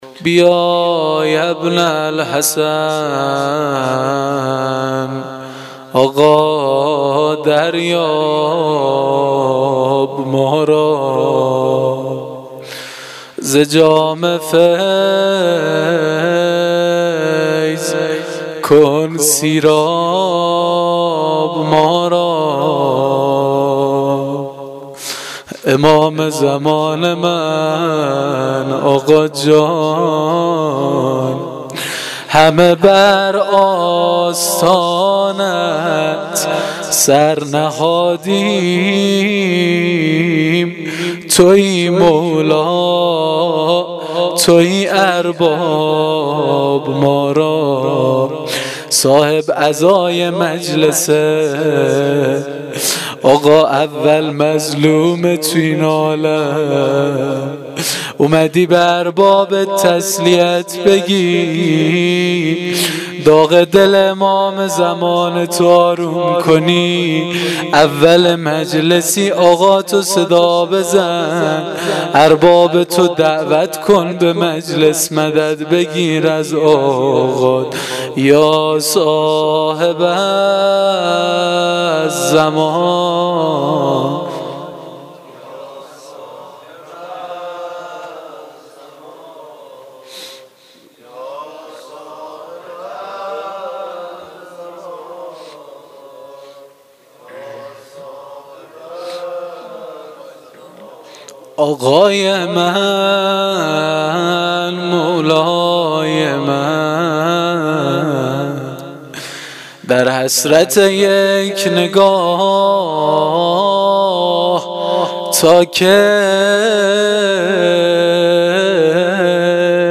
مناجات وروضه شب اربعین.mp3